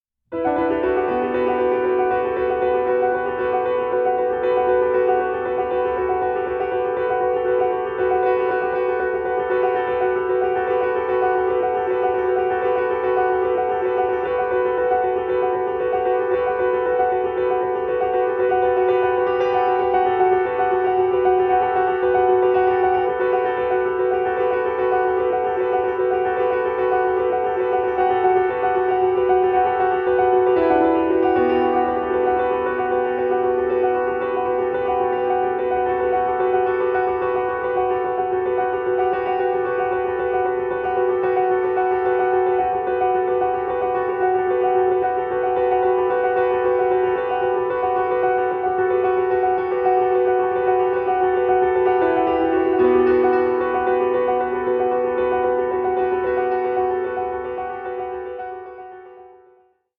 organist and keyboard player